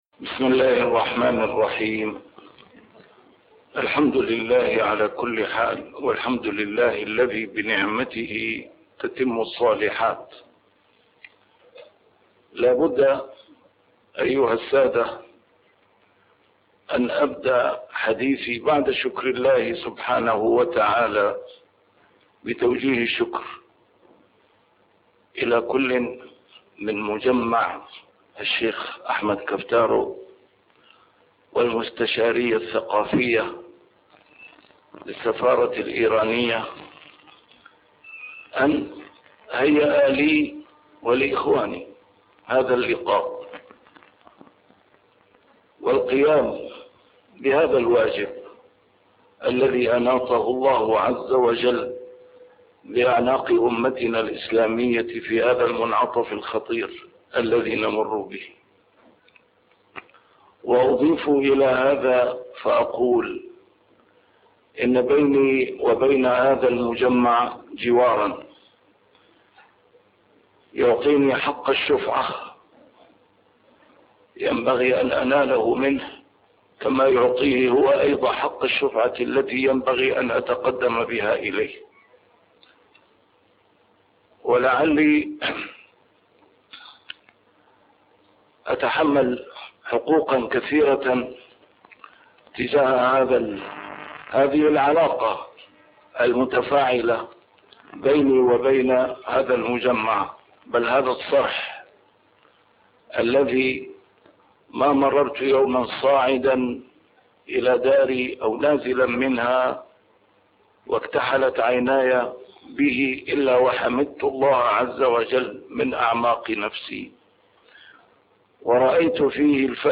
نسيم الشام › A MARTYR SCHOLAR: IMAM MUHAMMAD SAEED RAMADAN AL-BOUTI - الدروس العلمية - محاضرات متفرقة في مناسبات مختلفة - أسس الوحدة الإسلامية وضوابطها |محاضرة في مجمع أبي النور بدمشق